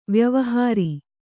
♪ vyavahāri